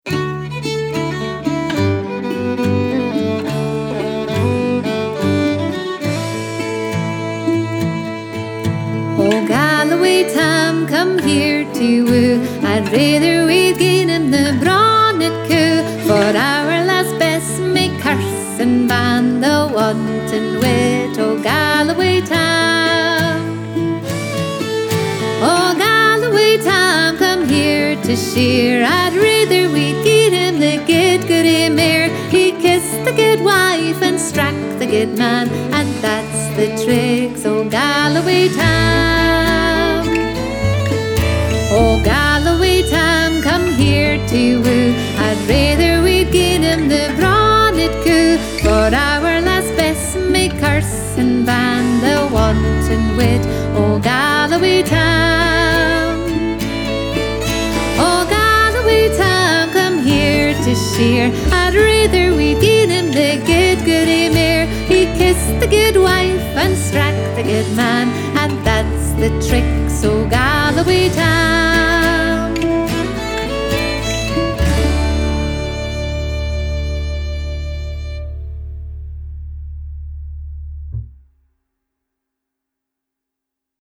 Scottish Music Download Galloway Tam MP3